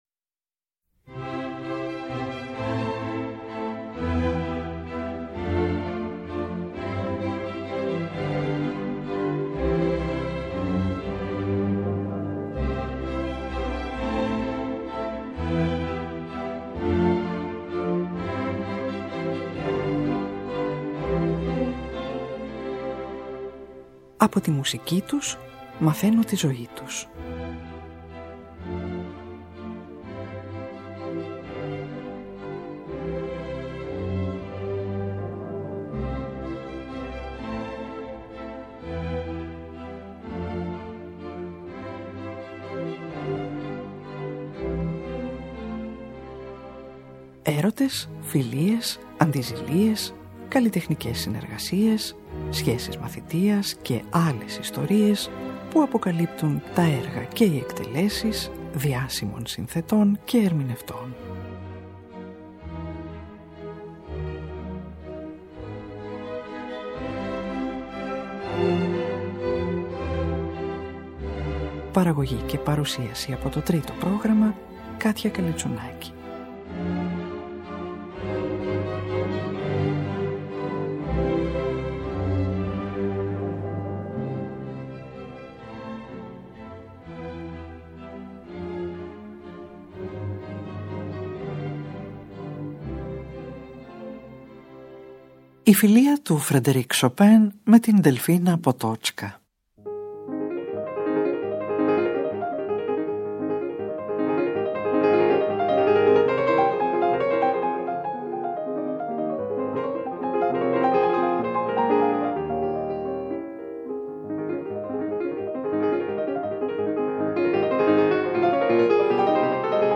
Το 2ο Κοντσέρτο παίζει ο Krystian Zimerman με τη Φιλαρμονική του Los Angeles υπο τον Carlo Maria Giulini, το Πρελούδιο και το Βάλς παίζουν η Martha Argerich και ο Claudio Arrau ενώ τη Μελωδία και 2 ακόμα τραγούδια του σε στίχους των Adam Mickiewitz και Stefan Witwicki ερμηνεύει η σοπράνο Elzbieta Szmytka με τον πιανίστα Malcolm Martineau.